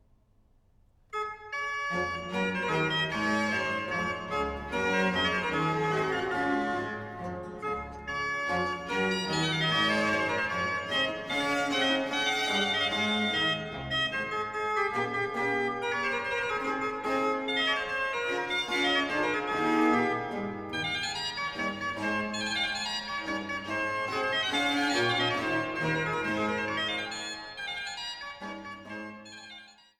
Silbermann-Orgel des Freiberger Domes